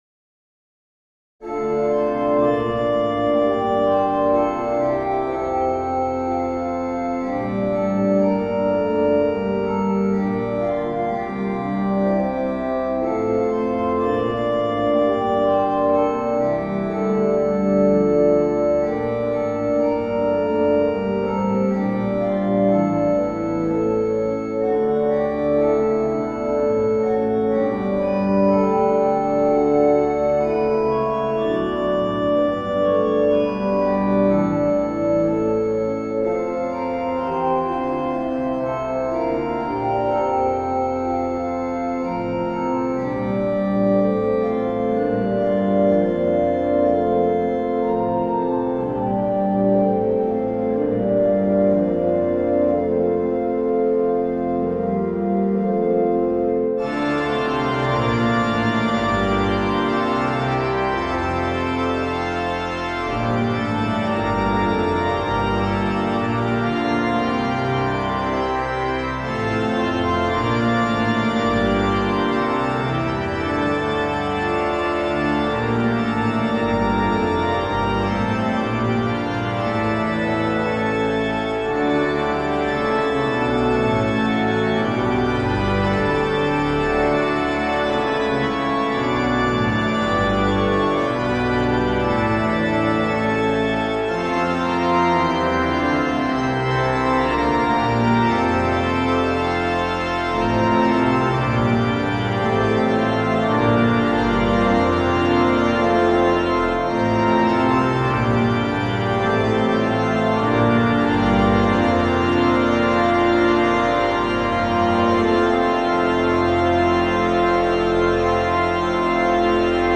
New hymn tune "Cottingham" setting of a new text for All Saints Day by John A. Dalles.